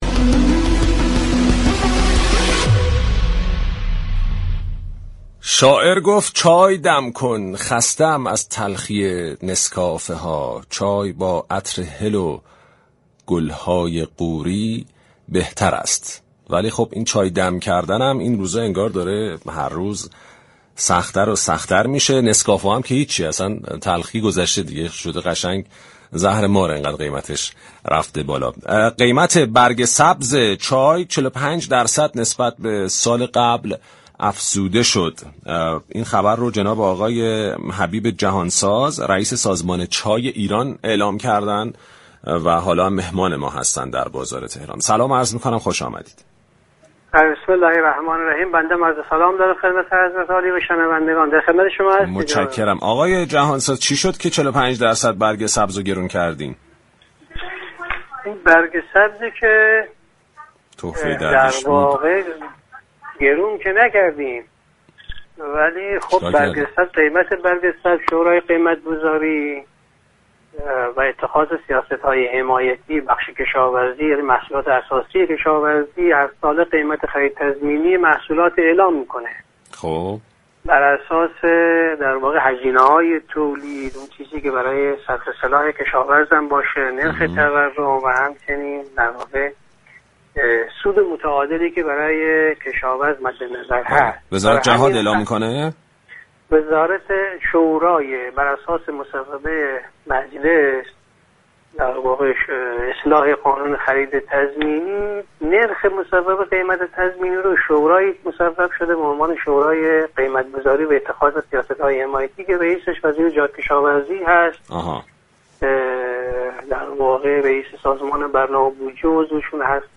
به گزارش پایگاه اطلاع رسانی رادیو تهران، حبیب جهانساز رئیس سازمان چای ایران در گفتگو با برنامه بازار تهران رادیو تهران درخصوص افزایش 45 درصدی قیمت خرید تضمینی برگ سبز چای گفت: ما قیمت چای را گران نكردیم، شورای سیاست‌گذاری هرساله قیمت خرید تضمینی محصولات را بر اساس هزینه های تولید، نرخ تورم و سود متعارف برای كشاورز اعلام می‌كند.